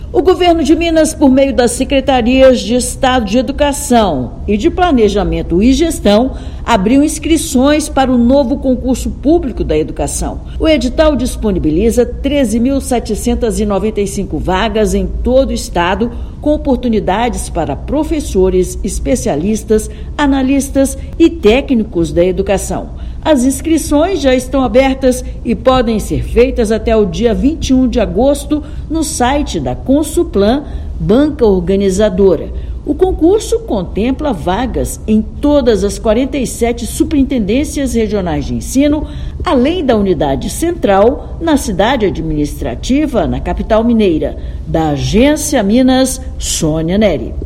Certame contempla diversas carreiras da rede estadual; inscrições vão até 21/8 e pedidos de isenção da taxa podem ser feitos até quinta-feira (24/7). Ouça matéria de rádio.